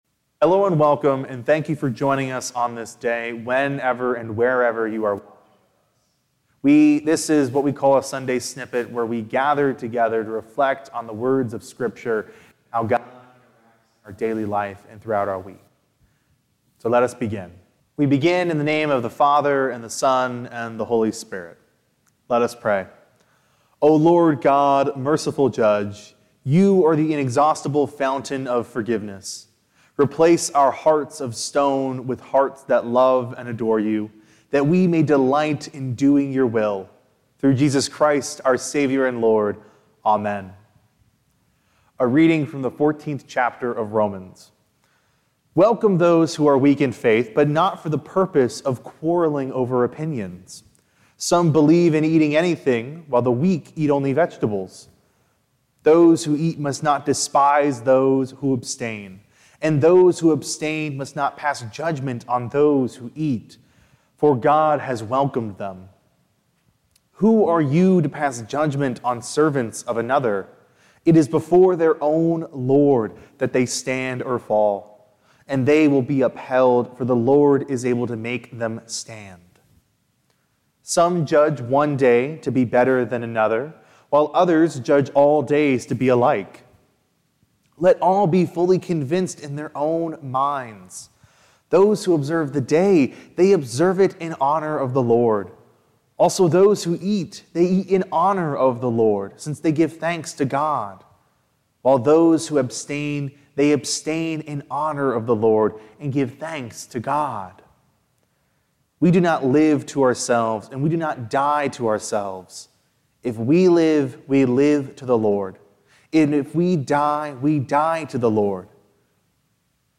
Recorded by the staff and volunteers at St. Paul Lutheran Church - Borchers in Seymour, Indiana.